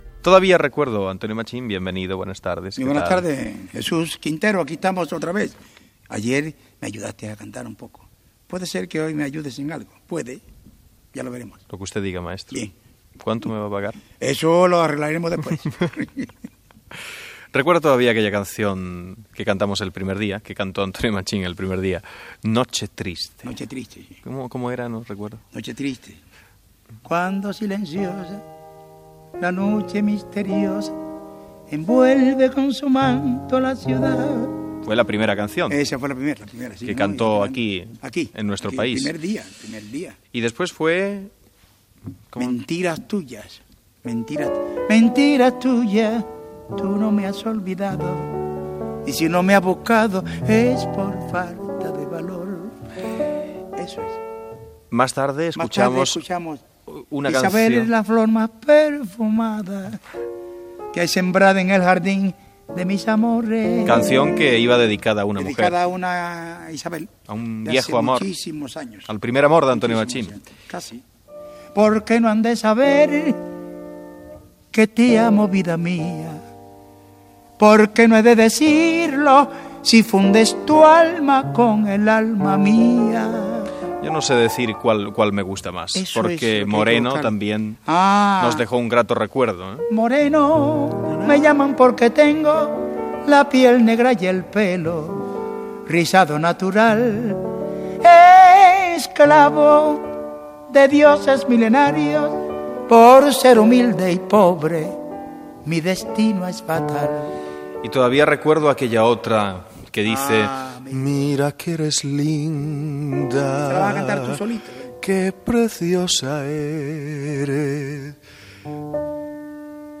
Quart dia de l'entrevista al cantant cubà Antonio Machín, que interpreta fragments d'algunes cançons en directe, acompanyat del piano
Entreteniment